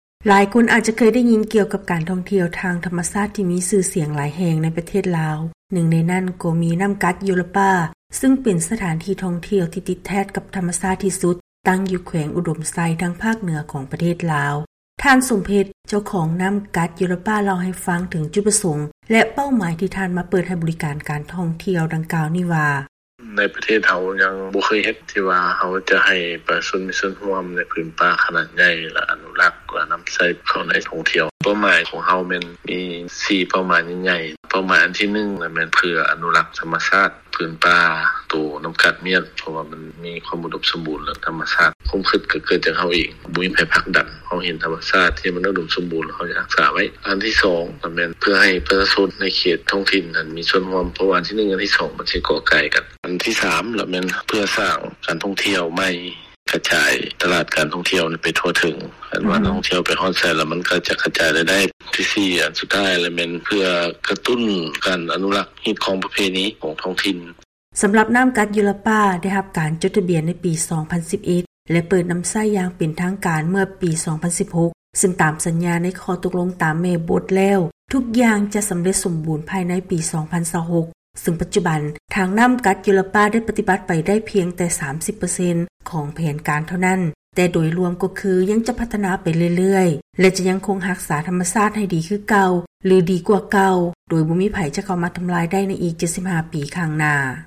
ເຊີນຮັບຟັງຂ່າວກ່ຽວກັບ ການທ່ອງທ່ຽວ ແລະມີສ່ວນຮ່ວມໃນທໍາມະຊາດຂອງ ນໍ້າກັດ ໂຢລະປາ.